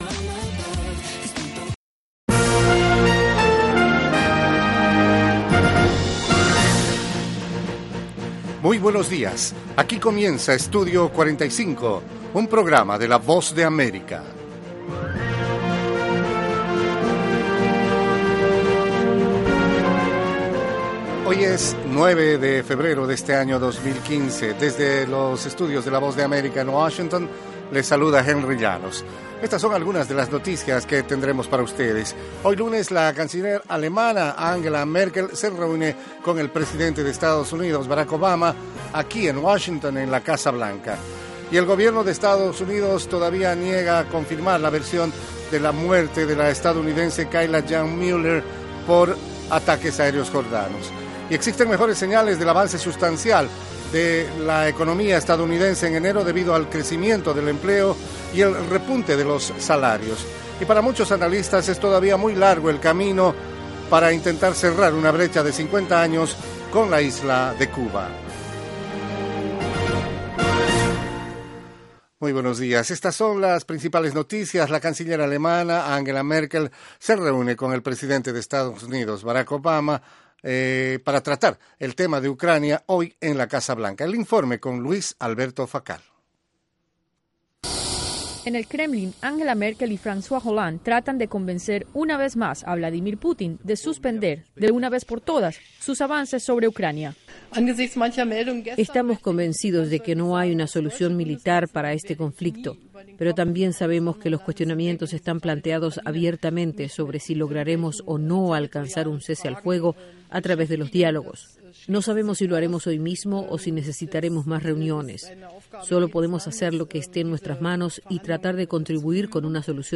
Treinta minutos de la actualidad noticiosa de Estados Unidos con análisis y entrevistas.